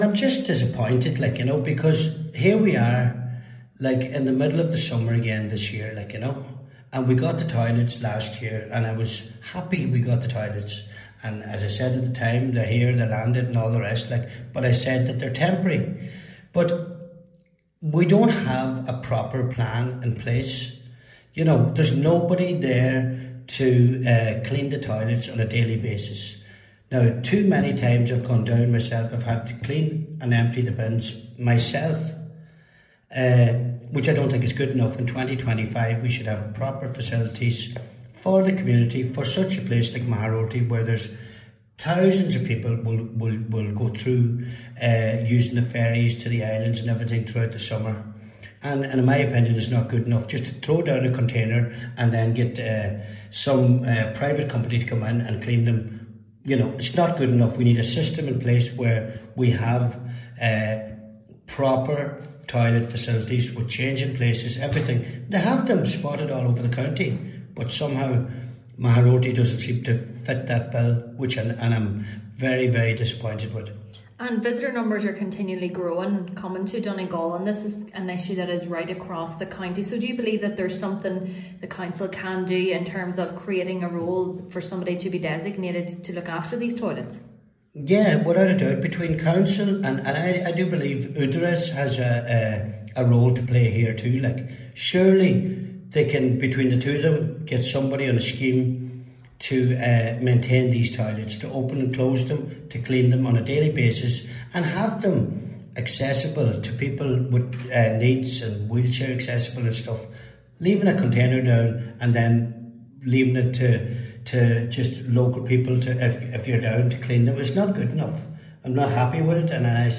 Councillor McGee says what is needed is proper accessible facilities: